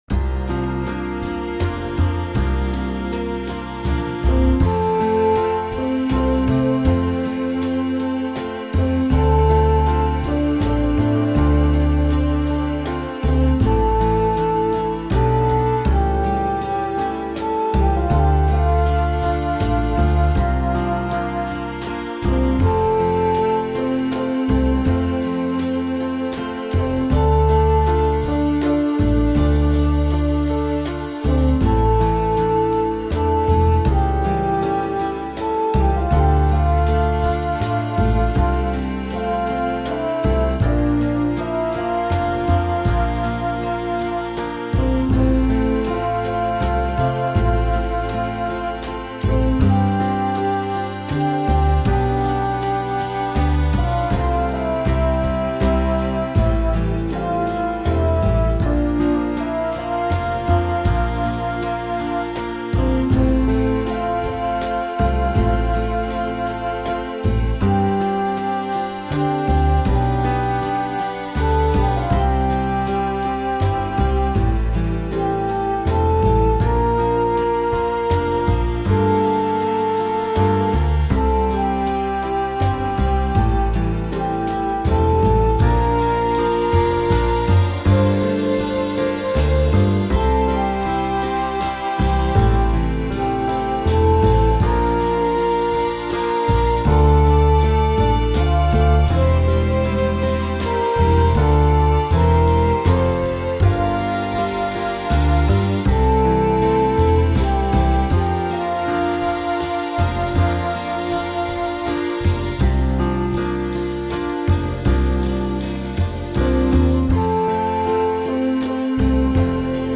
My backing is so relaxed it’s pretty much supine.